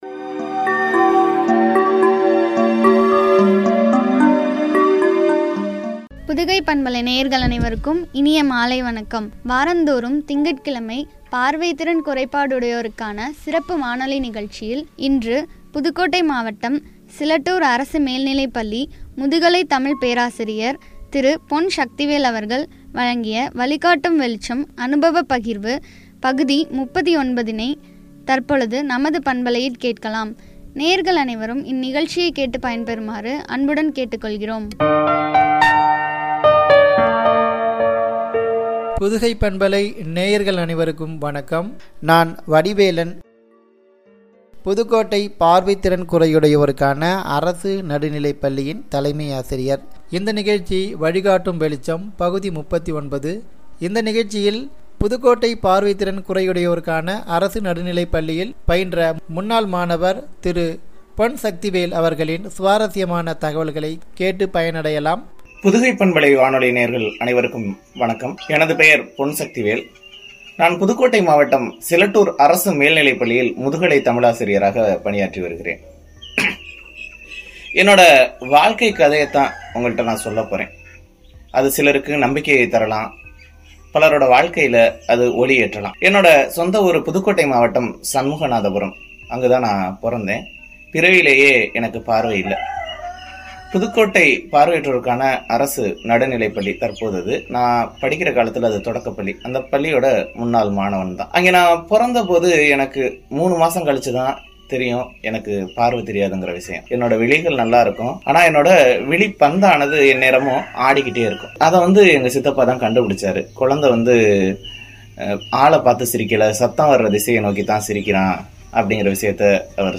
வழிகாட்டும் வெளிச்சம்- (பகுதி 39)” (அனுபவப் பகிர்வு)